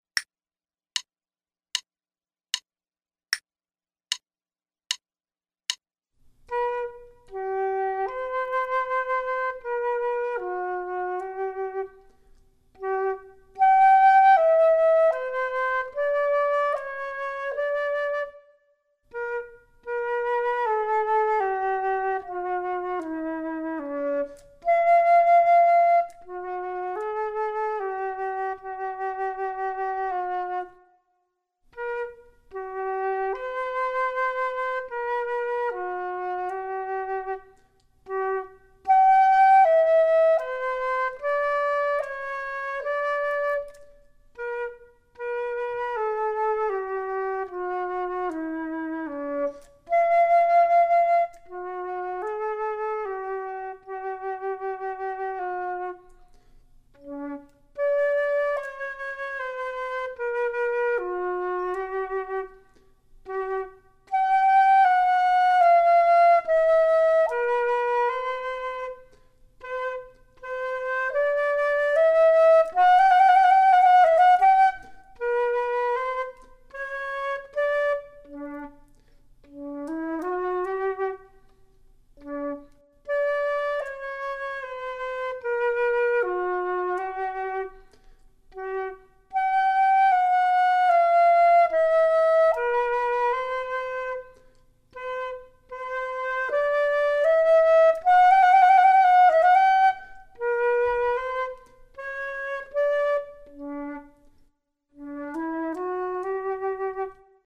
Flute 2 Only:
The tempo marking is poco adagio, which translates to "a little slow".
The metronome setting for this performance is quarter note = 76.